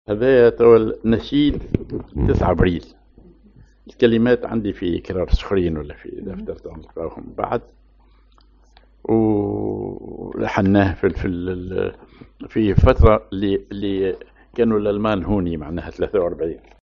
ماجور على الجهاركاه
genre نشيد